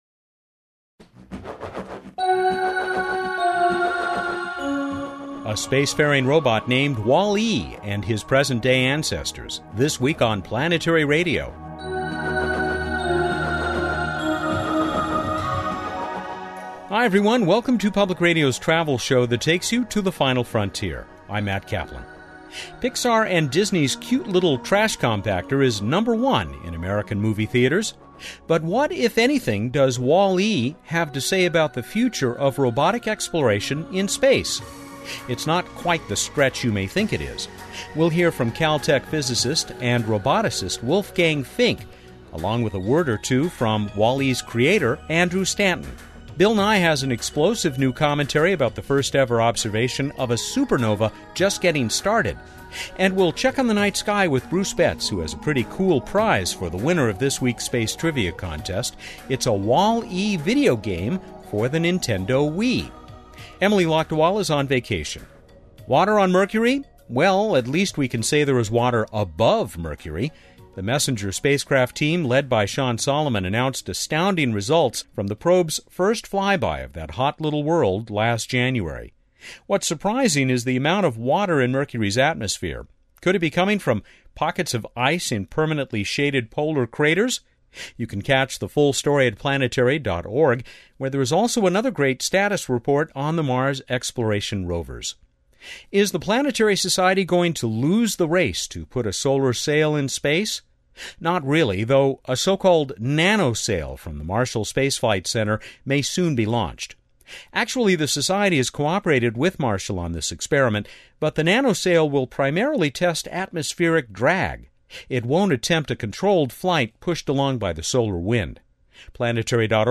Interview link (Windows Media Player) Interview link (MP3)